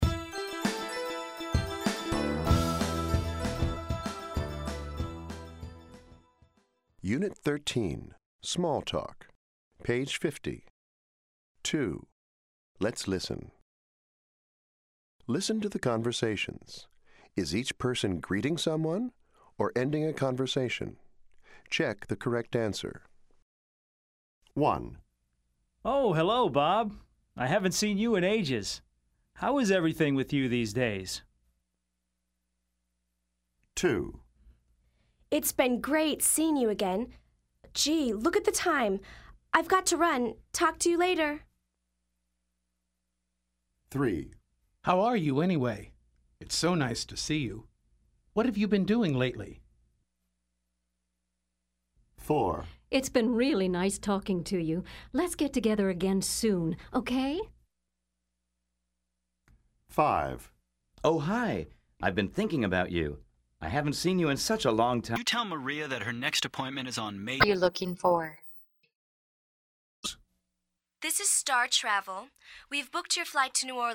Listen to the conversations. Is each person greeting someone or ending a conversation? Check the correct answer.